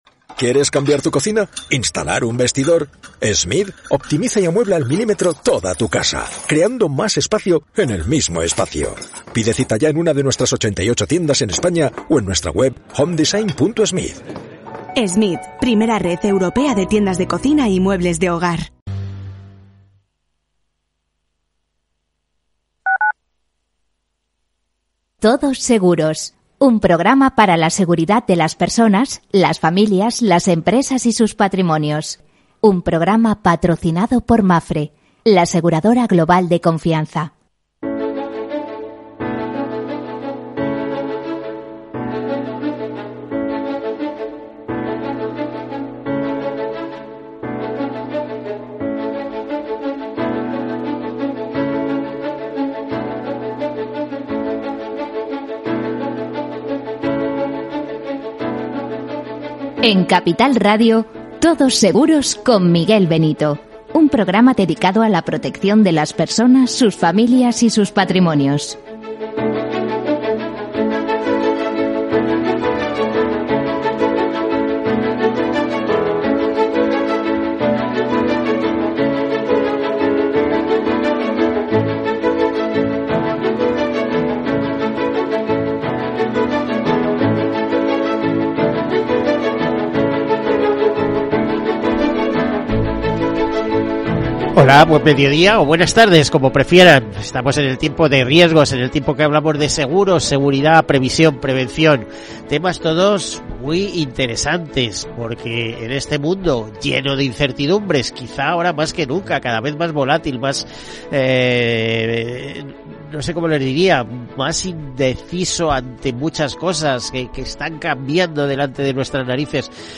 Evolución de otro anterior, desde el 9 de mayo de 2005, “Todos Seguros”es el programa radiofónico de referencia del sector asegurador, de la previsión y de la prevención.